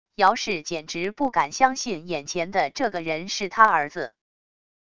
姚氏简直不敢相信眼前的这个人是她儿子wav音频生成系统WAV Audio Player